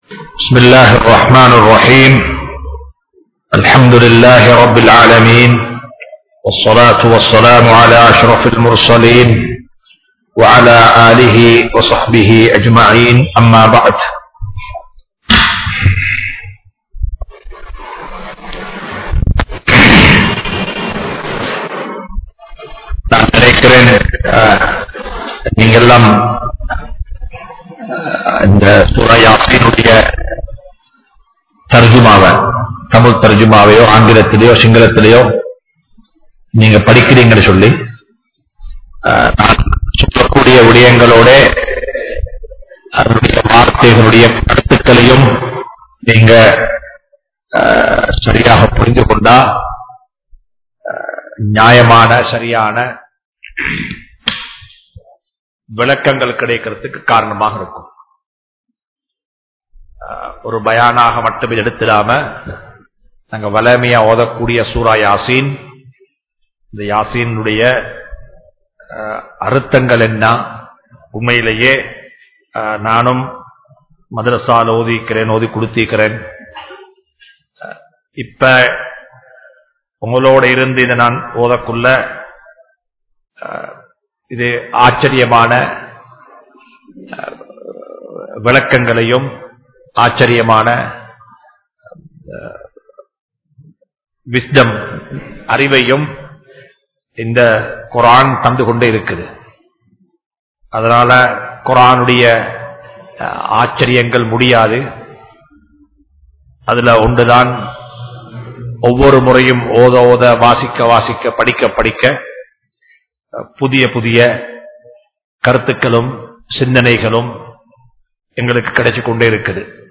Surah Yaseen(Thafseer Lesson 195) | Audio Bayans | All Ceylon Muslim Youth Community | Addalaichenai
Majma Ul Khairah Jumua Masjith (Nimal Road)